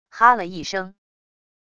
哈了一声wav音频